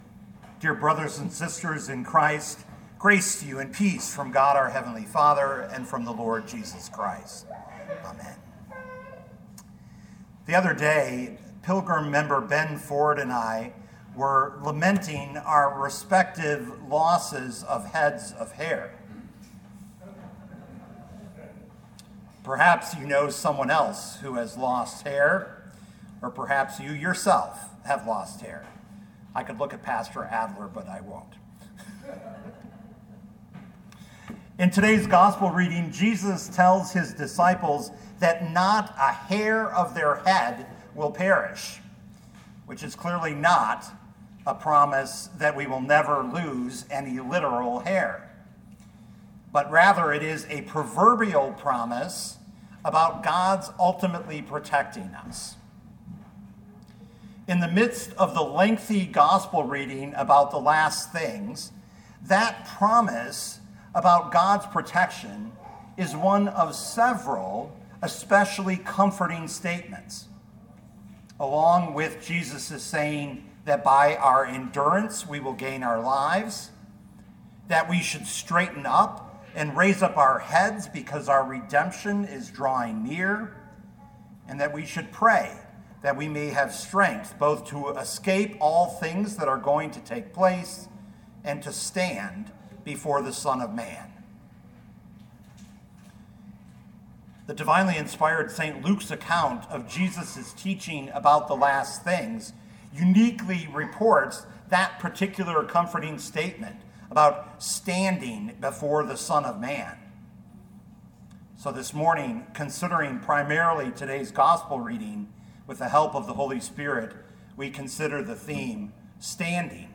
2025 Luke 21:5-36 Listen to the sermon with the player below, or, download the audio.